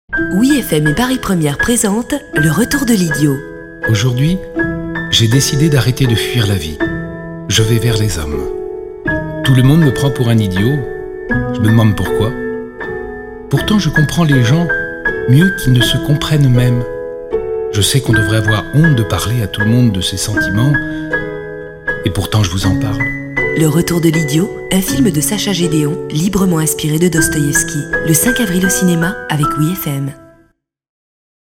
Bande-annonce su film